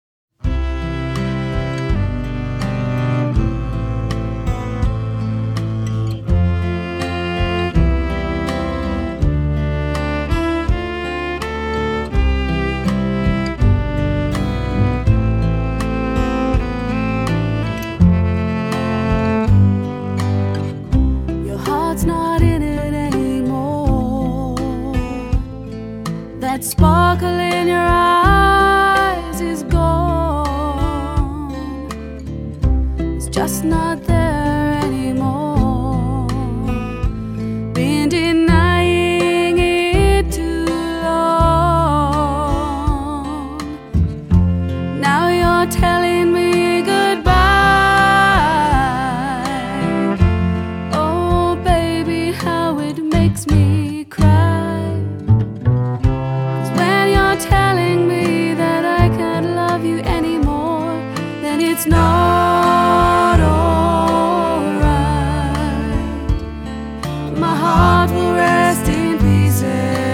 ★ 來自澳洲的民謠爵士三人組清新自然的民謠演繹，帶給您舒服暢快的聆聽享受！